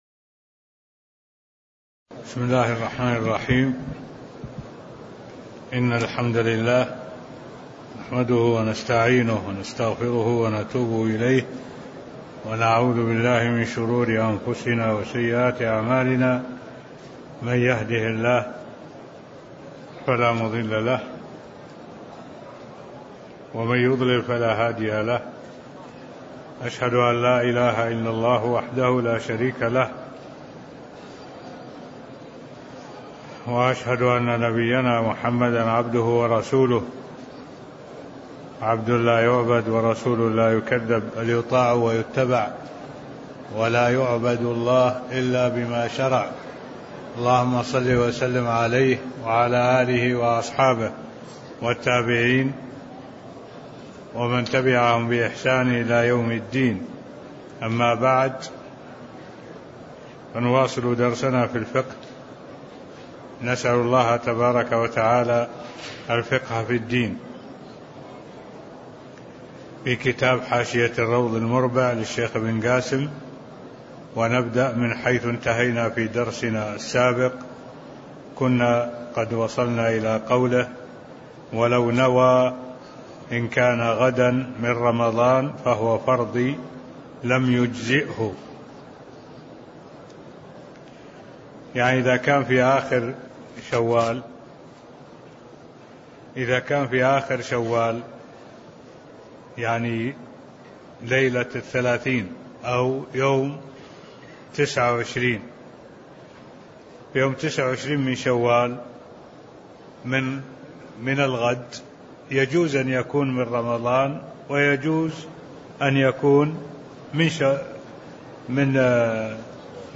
المكان: المسجد النبوي الشيخ: معالي الشيخ الدكتور صالح بن عبد الله العبود معالي الشيخ الدكتور صالح بن عبد الله العبود كتاب الصيام من قوله: (ولو نوى إن كان غداً من رمضان) (20)